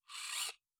robotics1.wav